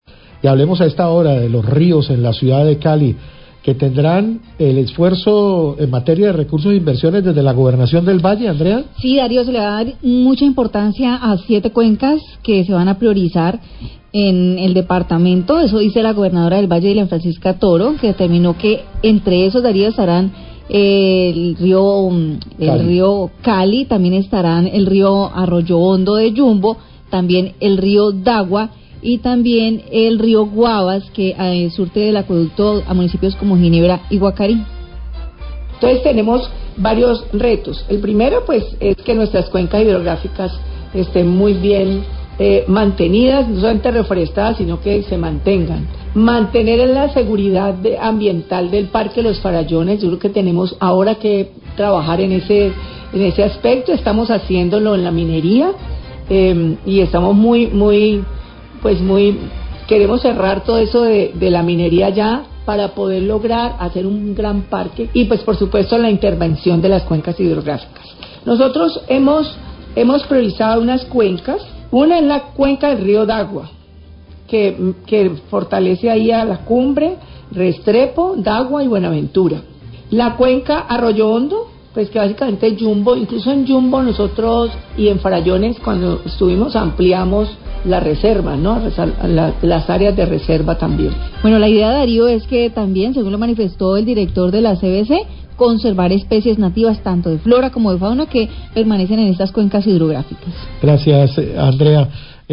Gobernadora del Valle habla de priorizar 7 cuencas del departamento
Radio